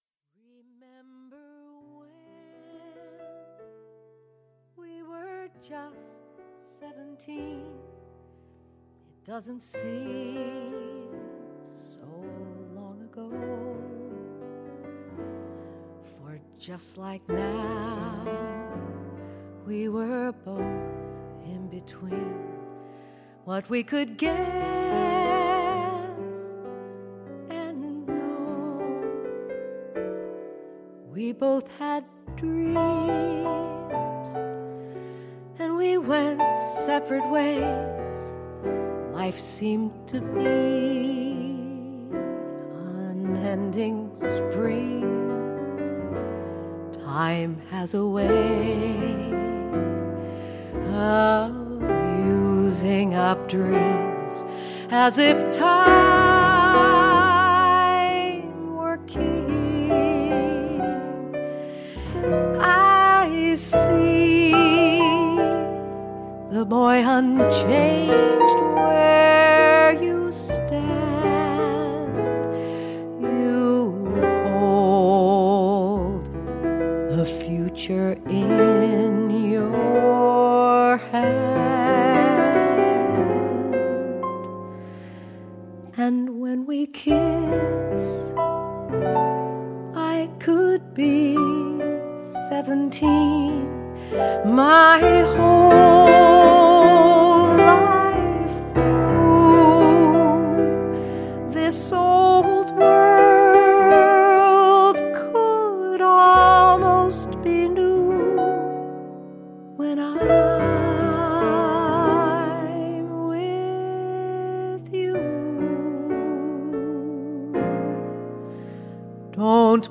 stunning piano